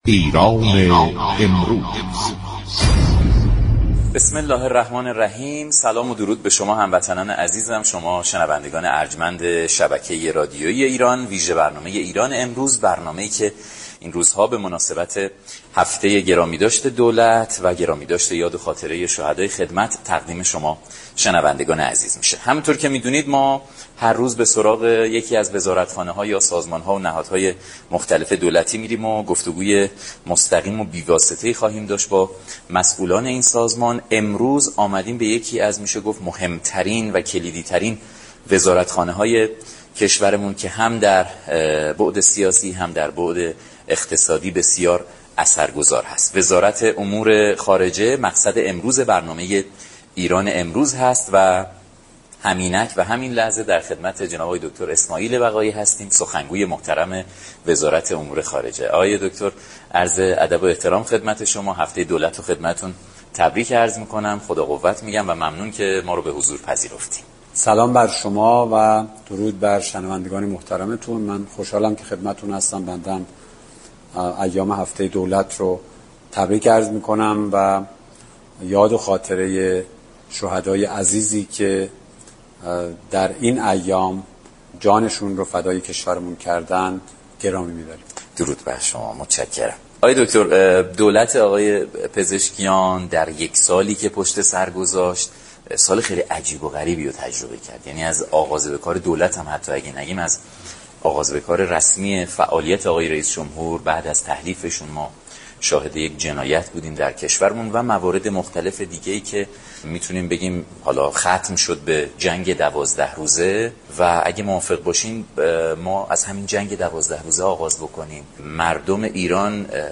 سخنگوی وزارت امور خارجه در برنامه ایران امروز گفت: در جنگ 12 روزه ایران هراسی از جنگ نداشت در عین حال دیپلماسی را هم گم نكرد.